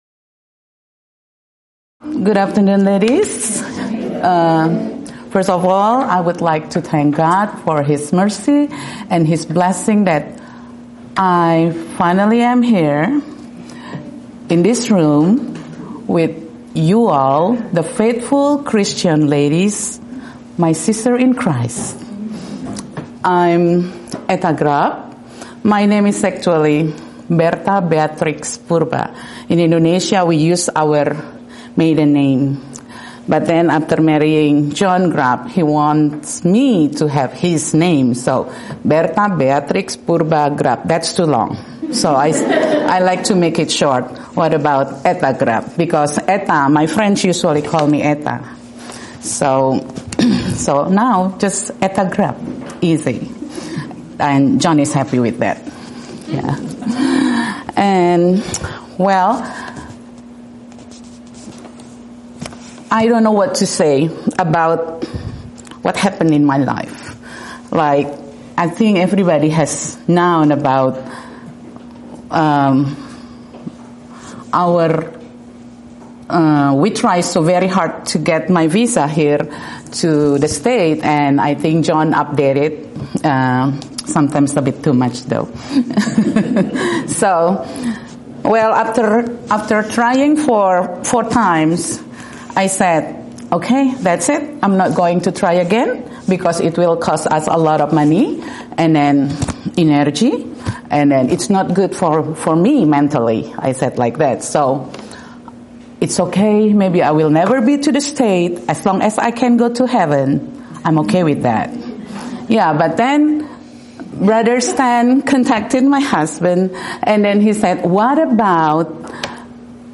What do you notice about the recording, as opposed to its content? Event: 17th Annual Schertz Lectures Theme/Title: Studies in Job